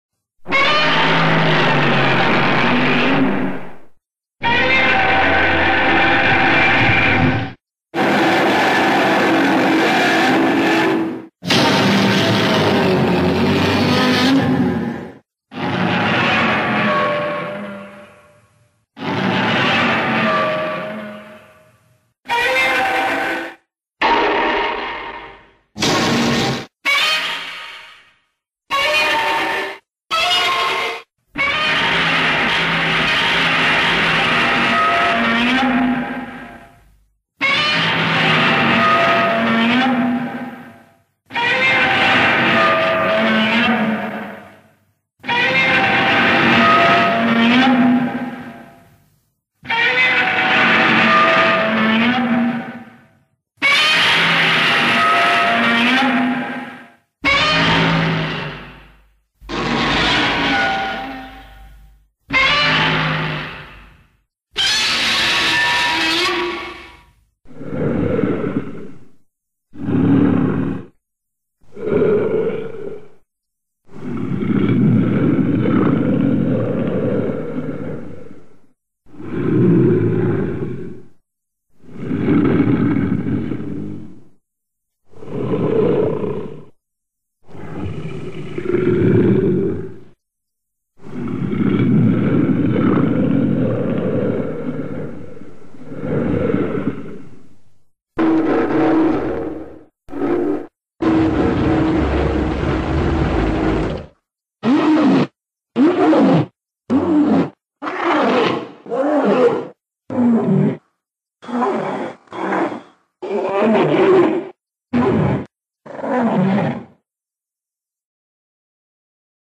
Звуки Годзиллы
Здесь вы найдете его мощный рев, грохот шагов, звуки разрушений и другие эффекты из фильмов и комиксов.
Серия звуков с мощным рёвом Годзиллы для монтажа